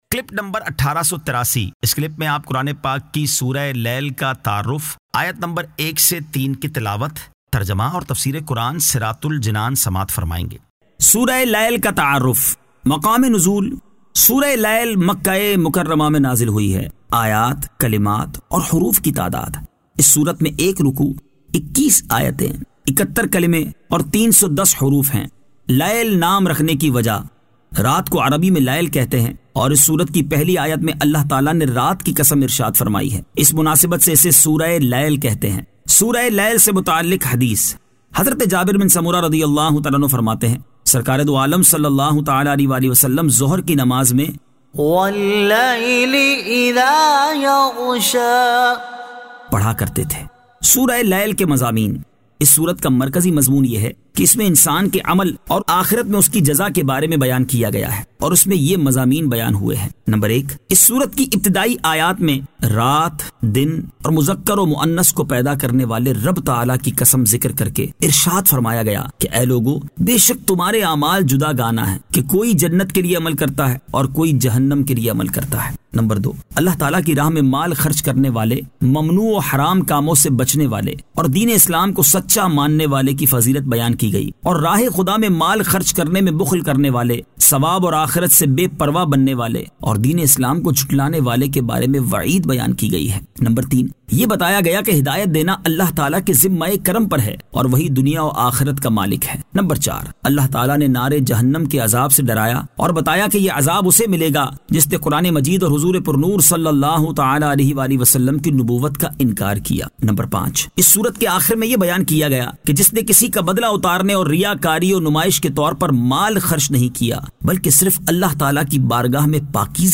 Surah Al-Lail 01 To 03 Tilawat , Tarjama , Tafseer